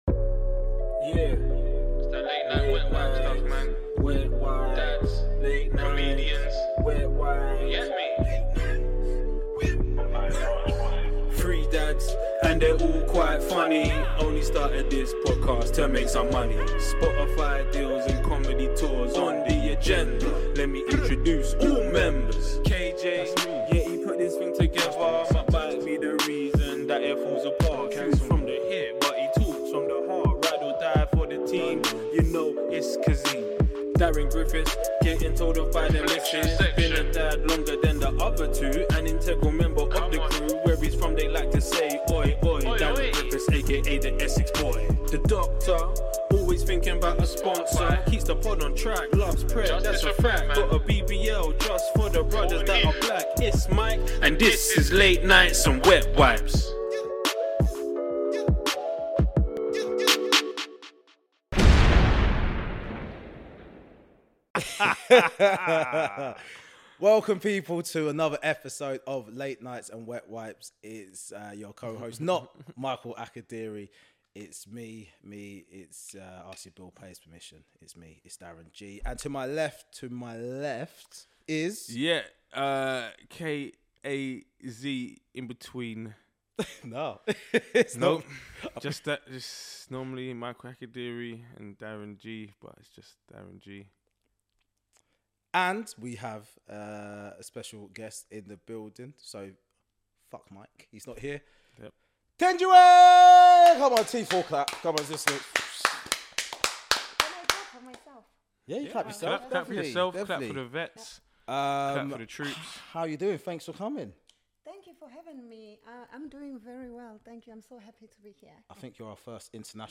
Not only are all three guys back in the building